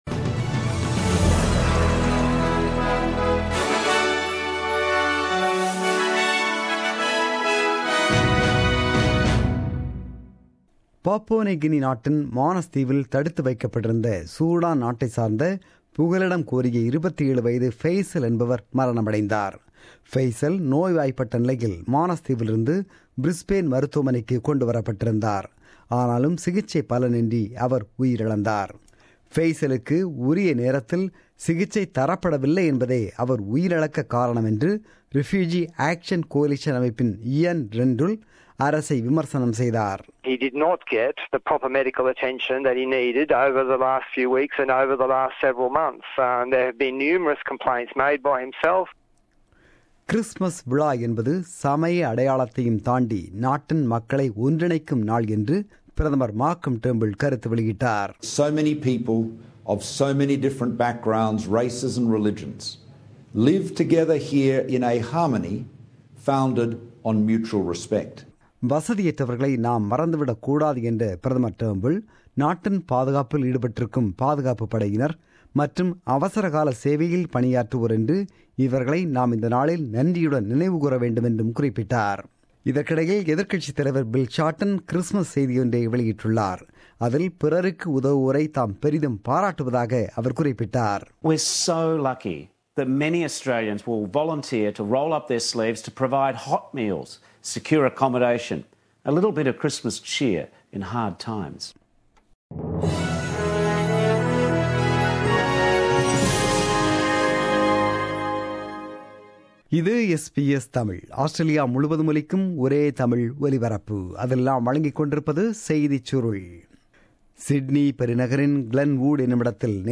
The news bulletin broadcasted on 25 December 2016 at 8pm.